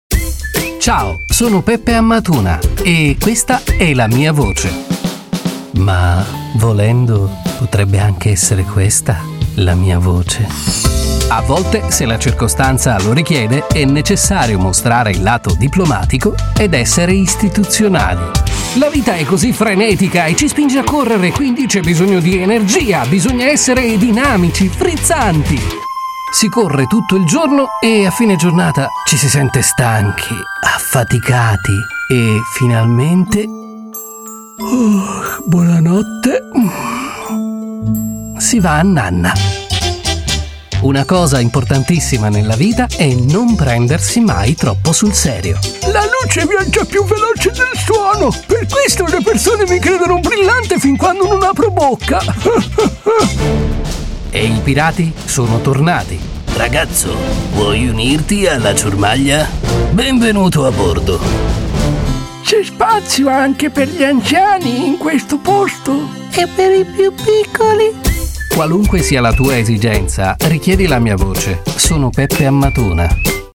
A versatile and multipurpose voice, suitable for any production. Extremely flexible native italian voice.
Sprechprobe: Sonstiges (Muttersprache):